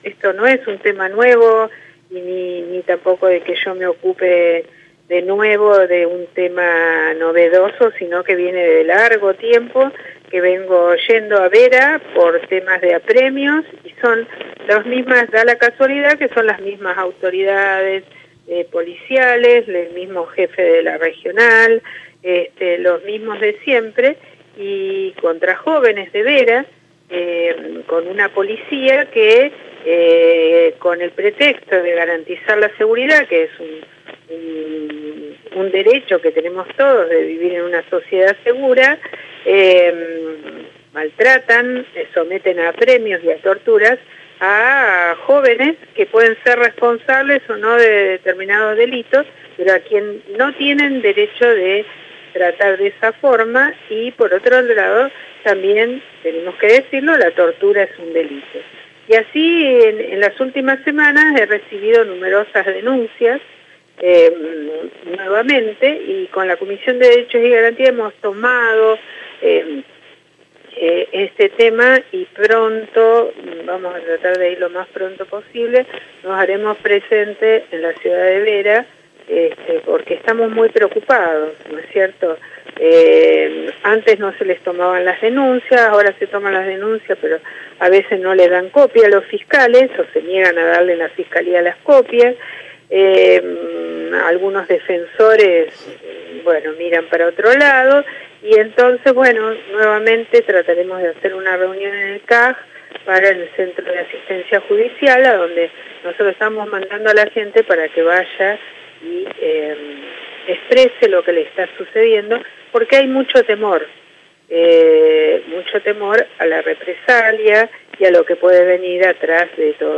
En dialogo con FM ACTIVA la legisladora criticó a la instituciones locales por la indiferencia que muestran ante estas situaciones y al accionar judicial.